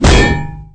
1 channel
tankColl.ogg